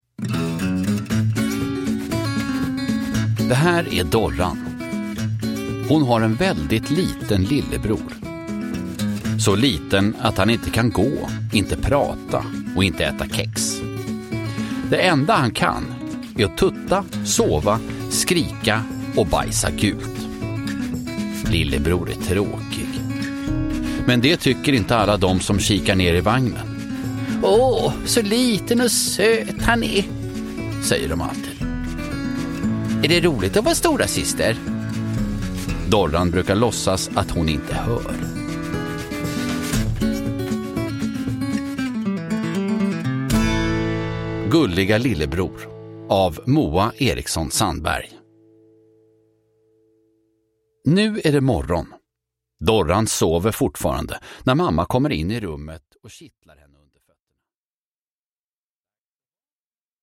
Gulliga lillebror – Ljudbok – Laddas ner